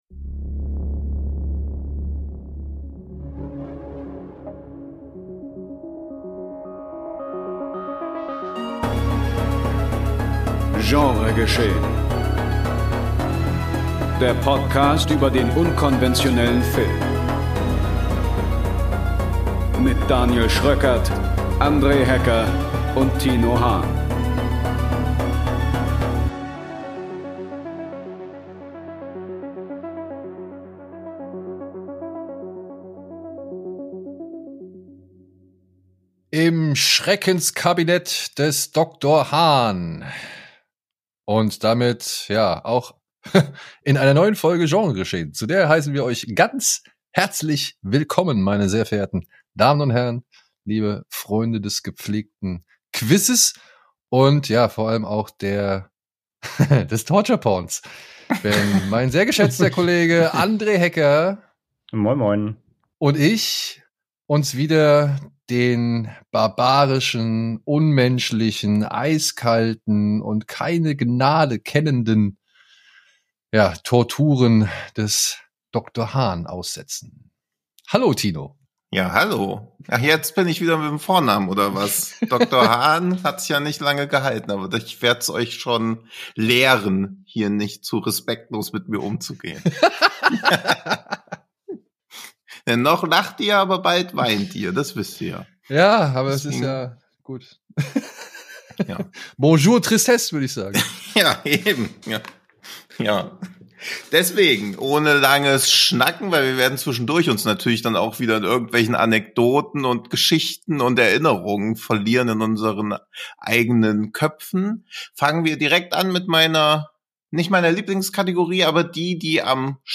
1 Spezial - Interview mit Veronika Franz & Severin Fiala (Des Teufels Bad / The Devil's Bath) 49:29